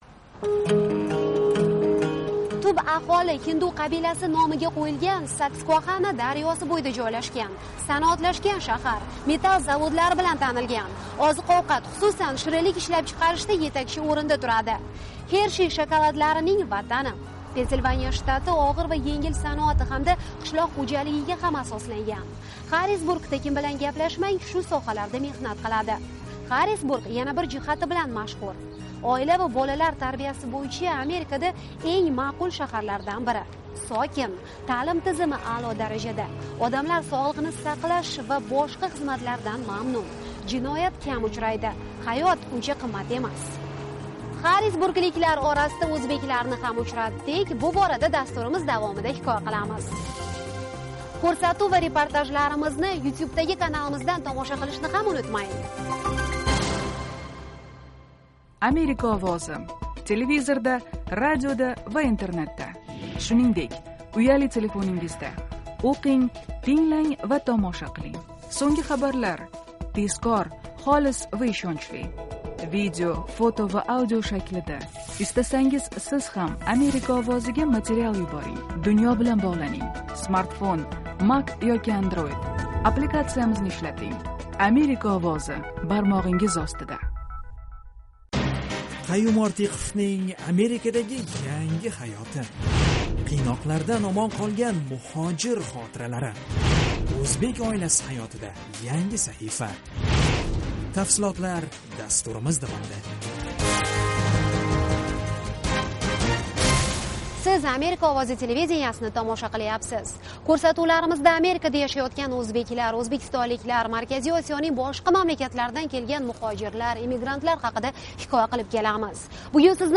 Interview with Uzbek Torture Victim